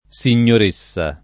Signoressa [ S in’n’or %SS a ]